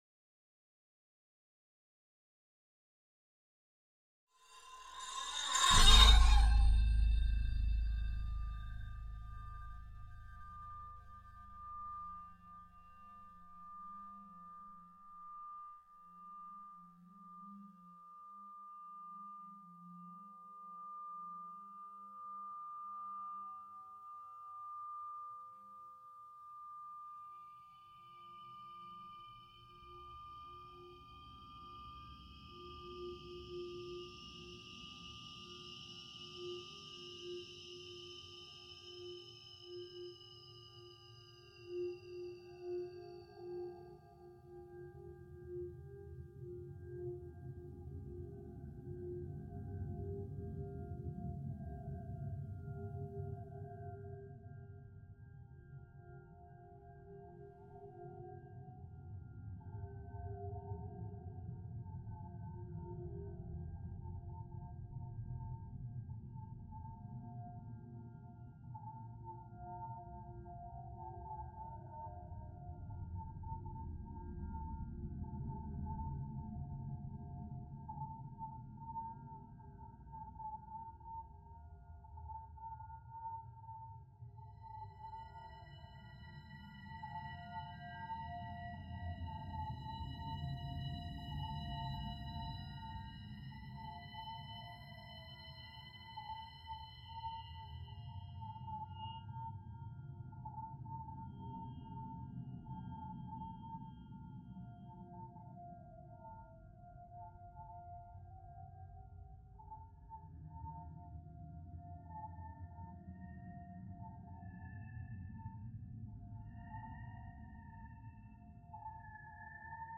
valentine_stereo_bnce_1aif.mp3